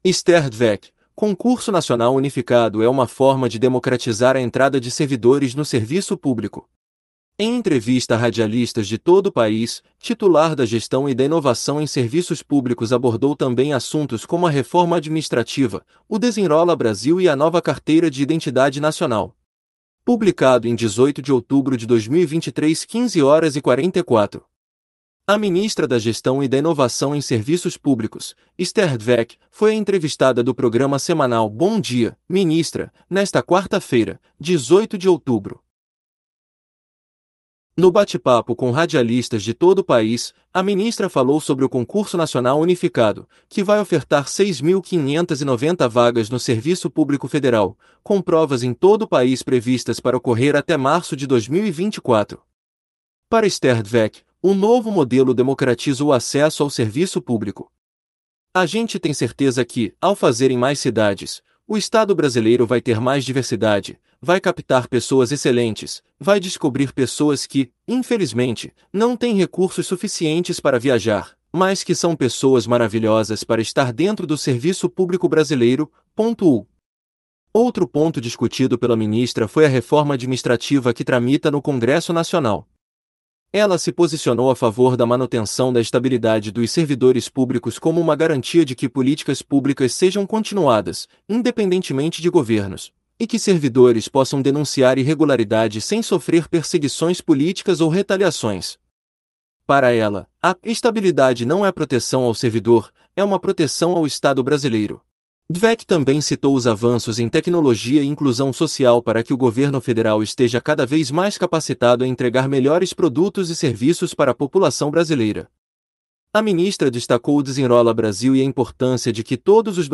Em entrevista a radialistas de todo o país, titular da Gestão e da Inovação em Serviços Públicos abordou também assuntos como a reforma administrativa, o Desenrola Brasil e a nova Carteira de Identidade Nacional